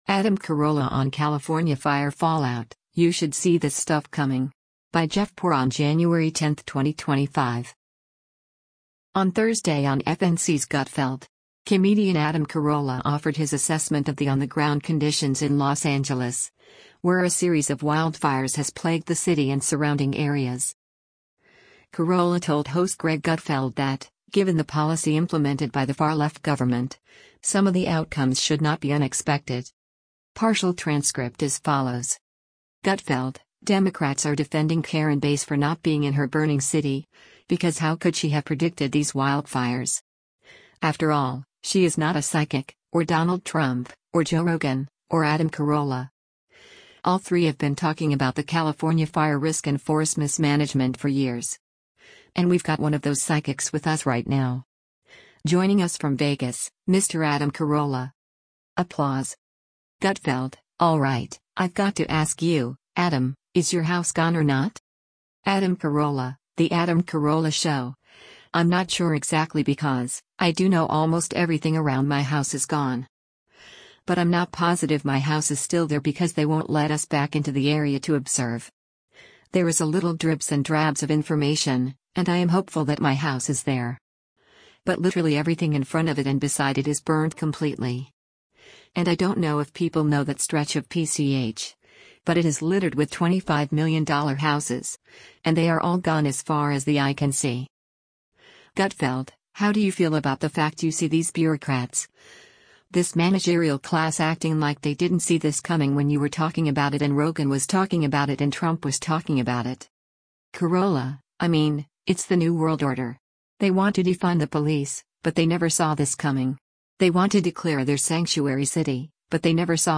On Thursday on FNC’s “Gutfeld!” comedian Adam Carolla offered his assessment of the on-the-ground conditions in Los Angeles, where a series of wildfires has plagued the city and surrounding areas.
Carolla told host Greg Gutfeld that, given the policy implemented by the far-left government, some of the outcomes should not be unexpected.